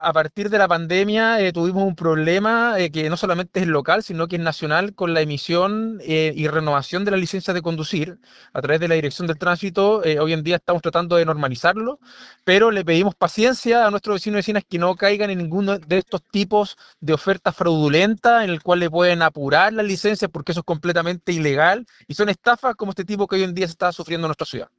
El alcalde Rodrigo Wainraihgt, enfatizó en el llamado a la comunidad a tener paciencia con estos trámites, señalando que están conscientes que desde la pandemia ha sido un proceso que extiende sus plazos ante la alta demanda en la capital regional.